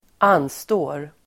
Uttal: [²'an:stå:r]